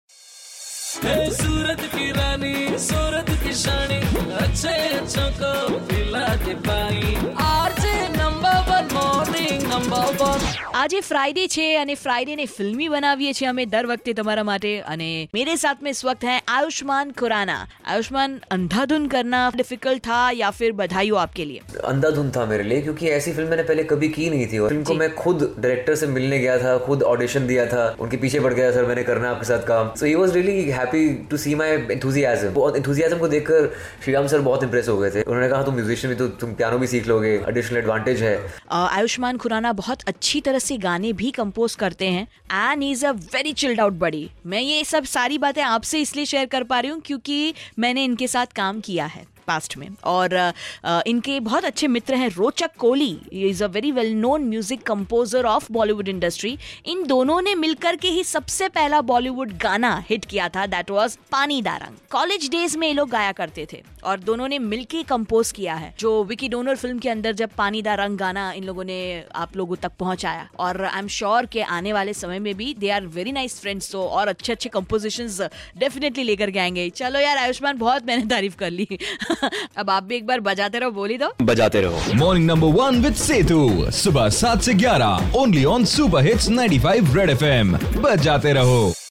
IN CONVERSATION WITH AYUSHMAN KHURANA